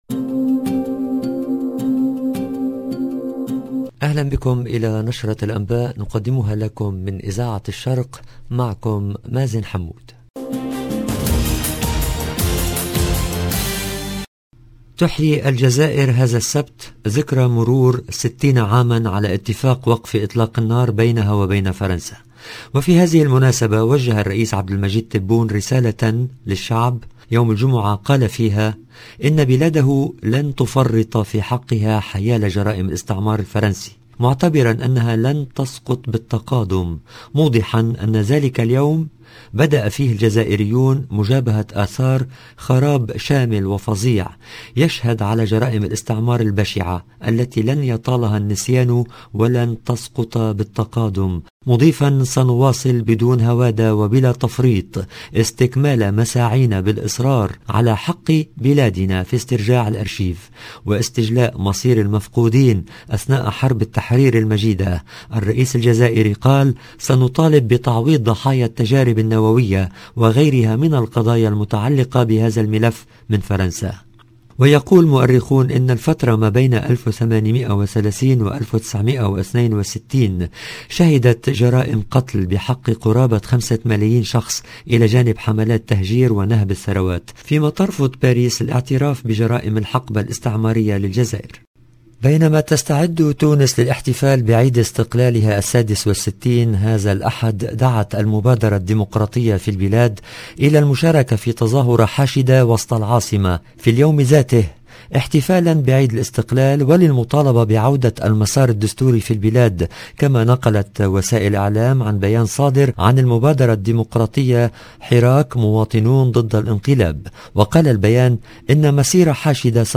EDITION DU JOURNAL DU SOIR EN LANGUE ARABE DU 18/3/2022